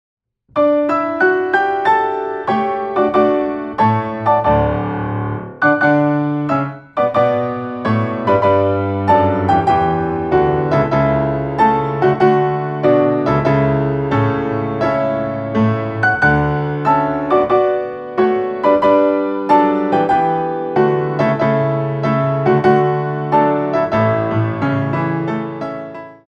Grand Battements - March
2 bar intro 4/4
32 bars